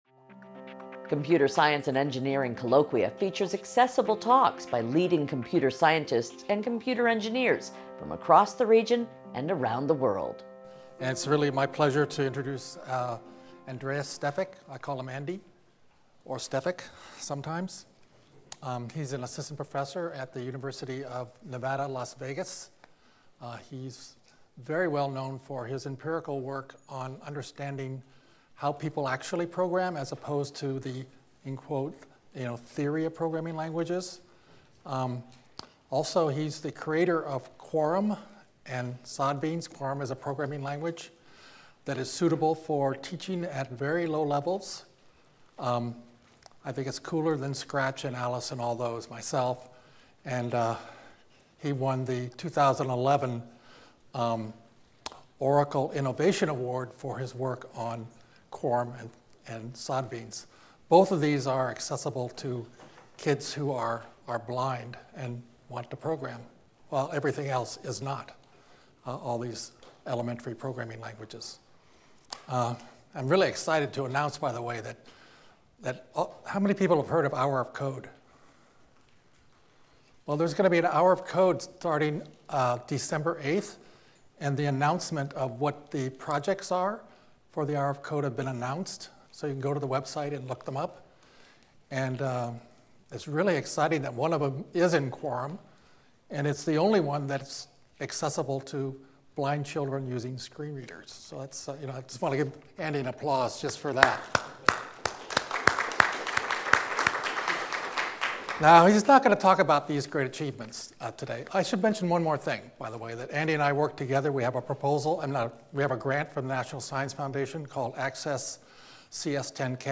University of Nevada, Las Vegas